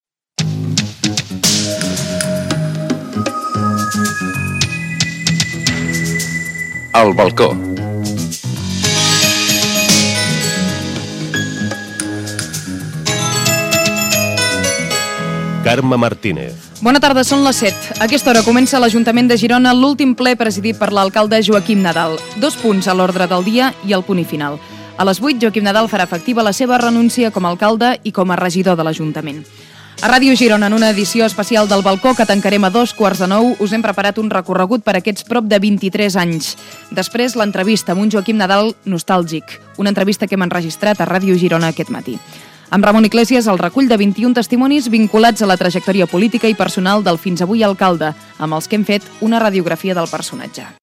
El Balcó: comiat de Joaquim Nadal - Ràdio Girona, 2002